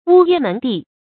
烏衣門第 注音： ㄨ ㄧ ㄇㄣˊ ㄉㄧˋ 讀音讀法： 意思解釋： 指世家望族。